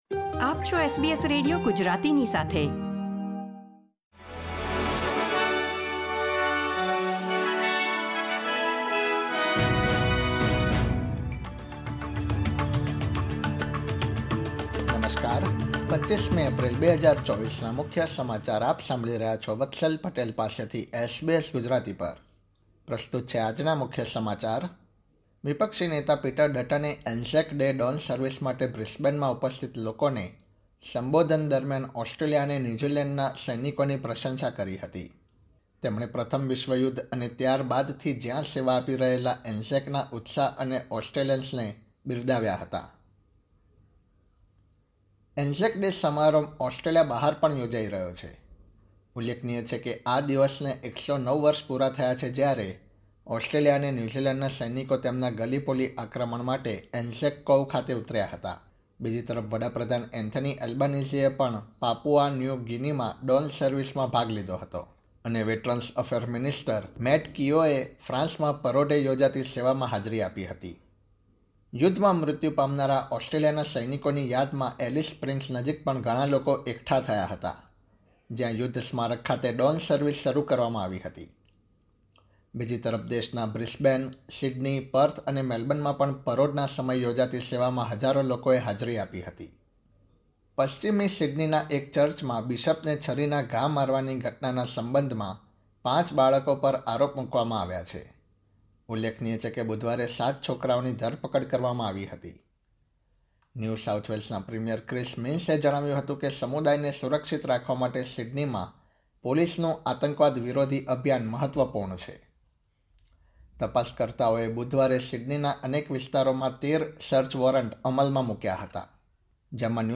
SBS Gujarati News Bulletin 25 April 2024